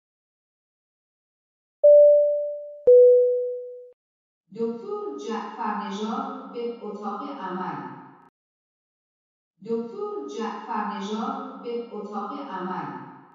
دانلود صدای پیجر بیمارستان ایرانی از ساعد نیوز با لینک مستقیم و کیفیت بالا
جلوه های صوتی